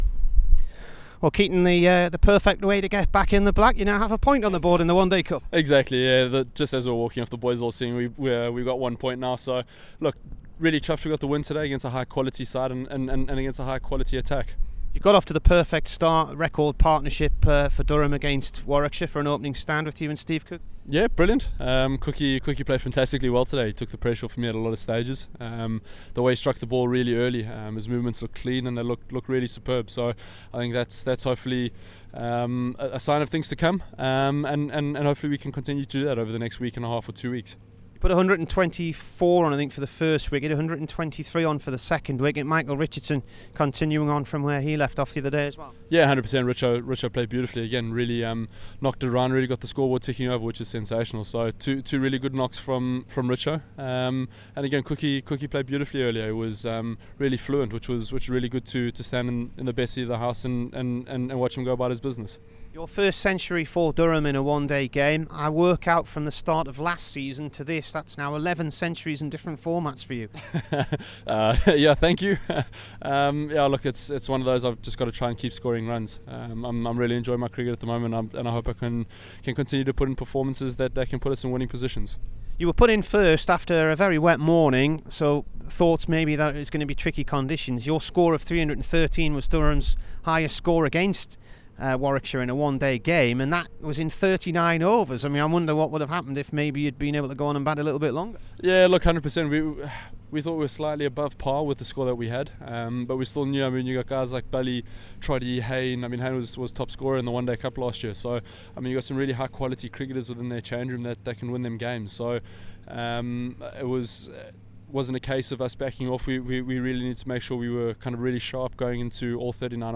KEATON JENNINGS INT
Here's the Durham one day captain after a career best 139 in the one day win at Edgbaston.